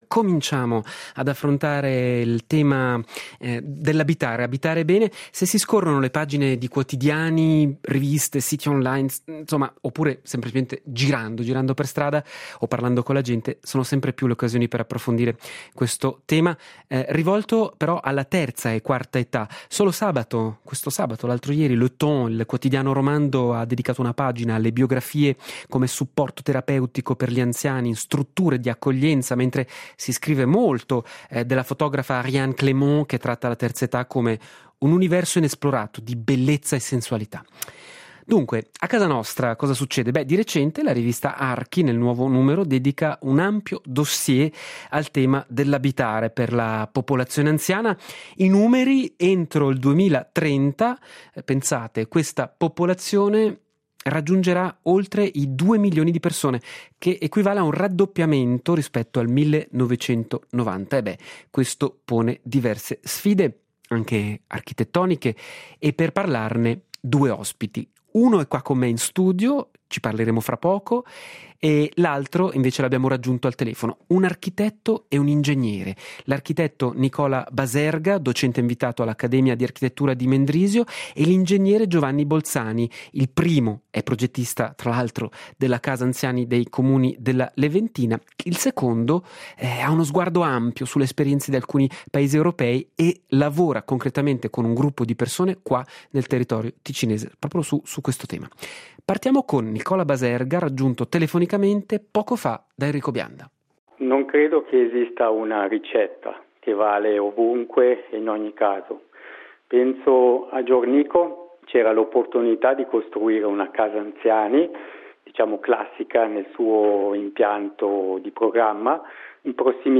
A colloquio